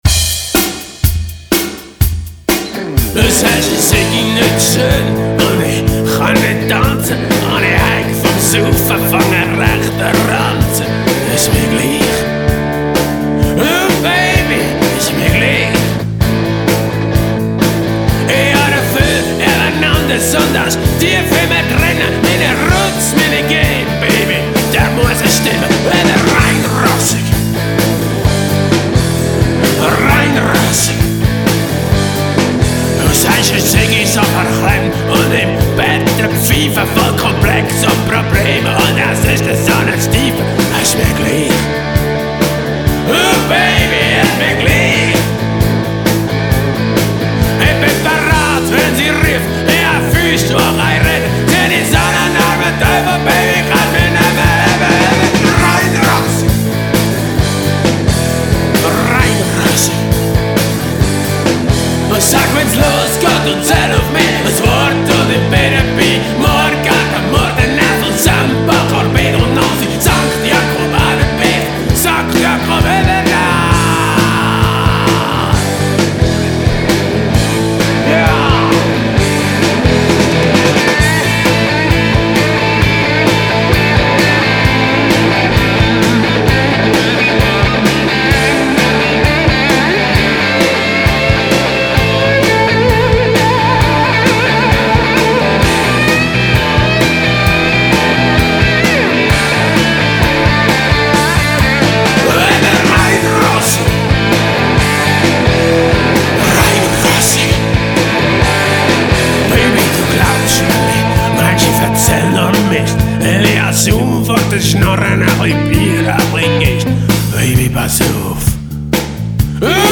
Schlagzeug
Bass
E-Gitarre
Gesang